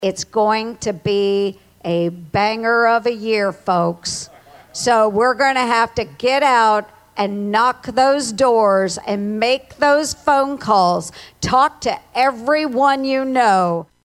ERNST, WHO ANNOUNCED SHE WOULD NOT RUN FOR RE-ELECTION FOUR MONTHS AGO, JOINED HINSON THIS PAST WEEKEND AT A CAMPAIGN EVENT IN ADEL.
TO CHEERS FROM THE CROWD. ERNST HINTED AT THE STAKES IN A SO-CALLED MIDTERM ELECTION, WHEN CAMPAIGNS CAN BE TOUGHER FOR CANDIDATES FROM THE SAME PARTY AS A SITTING PRESIDENT.